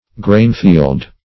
Grainfield \Grain"field`\ (gr[=a]n"f[=e]ld`), n.